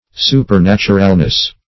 Supernaturalness \Su`per*nat"u*ral*ness\, n.